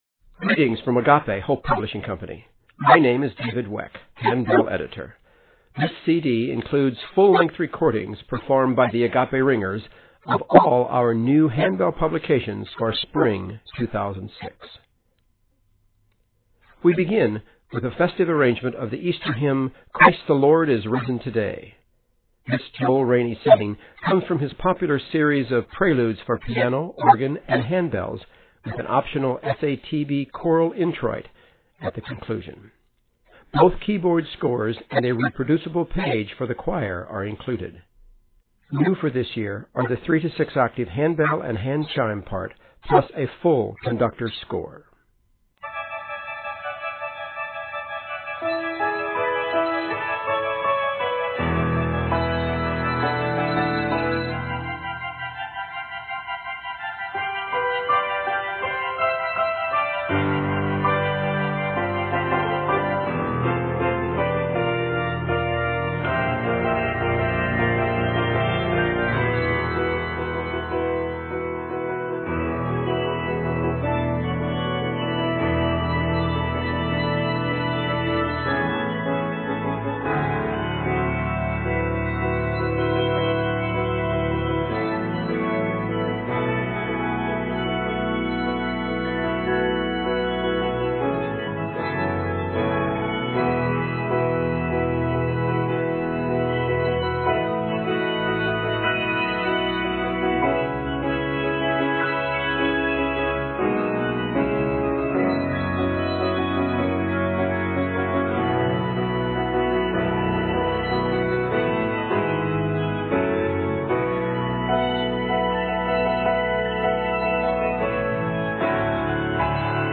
festive Easter prelude